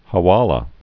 (hə-wälə)